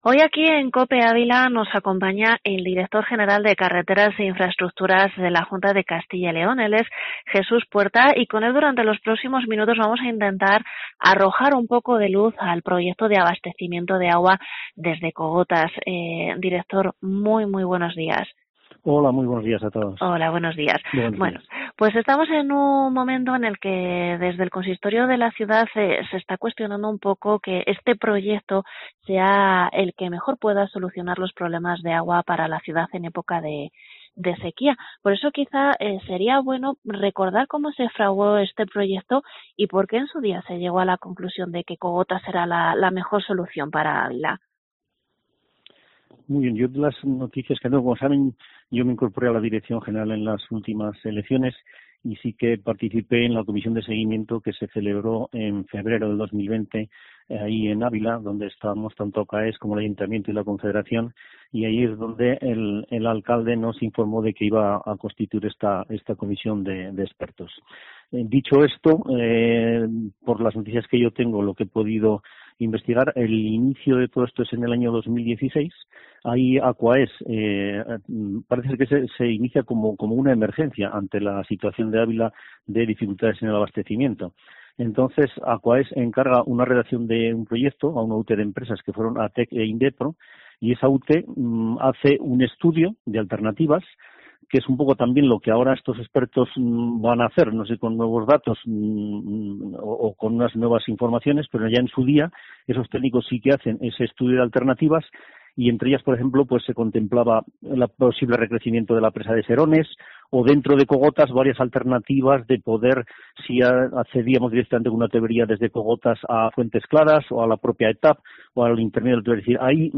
Entrevista a Jesús Puerta, director general de Carreteras e Infraestructuras